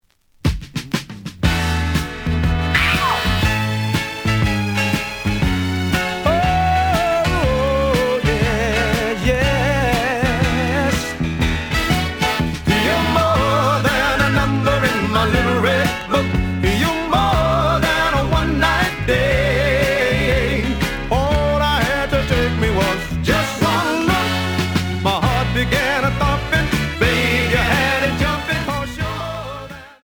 試聴は実際のレコードから録音しています。
●Genre: Rhythm And Blues / Rock 'n' Roll
●Record Grading: VG+ (盤に若干の歪み。多少の傷はあるが、おおむね良好。)